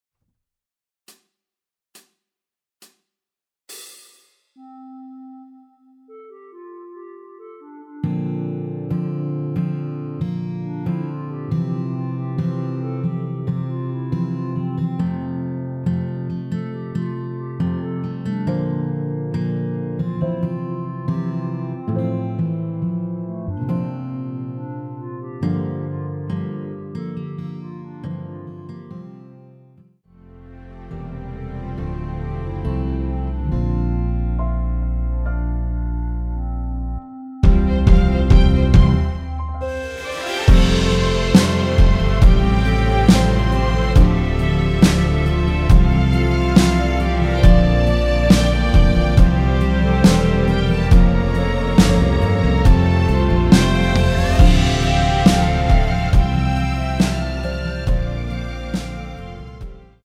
전주 없이 시작하는 곡이라서 시작 카운트 만들어놓았습니다.(미리듣기 확인)
원키에서(-3)내린 (1절앞+후렴)으로 진행되는 멜로디 포함된 MR입니다.
앞부분30초, 뒷부분30초씩 편집해서 올려 드리고 있습니다.